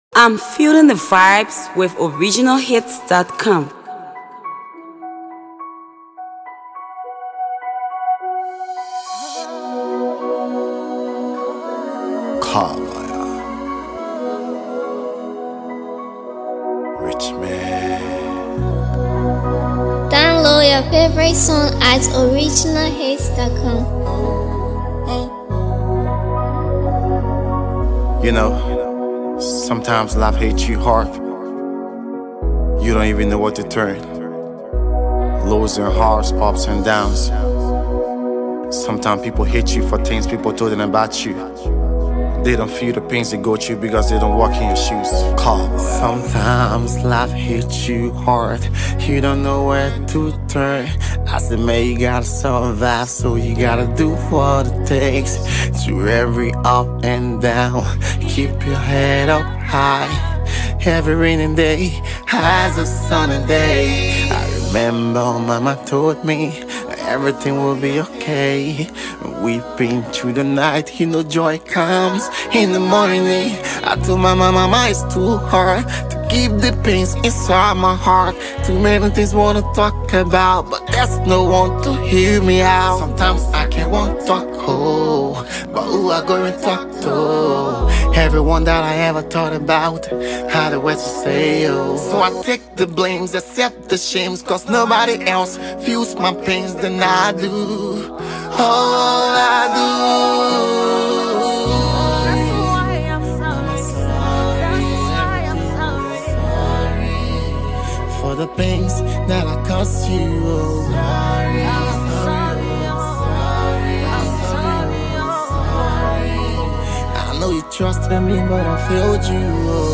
Liberian sensational gospel artist
He features Hipco star
heartfelt jam